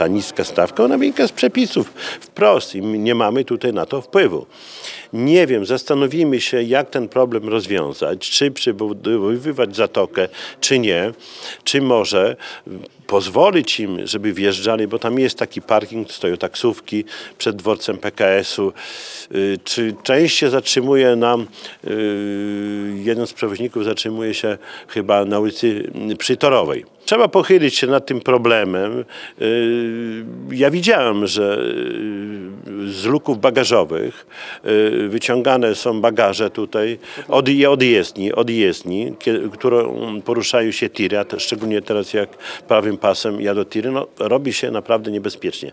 W odpowiedzi Czesław Renkiewicz, prezydent Suwałk nie krył, że problem zna, niemniej jego rozwiązanie jest problematyczne.